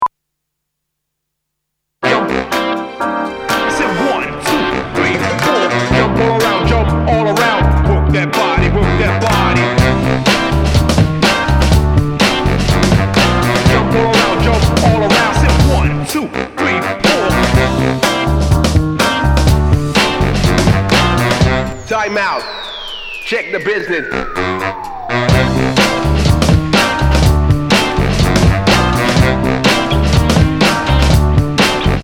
jazz medium energetic
tuba bass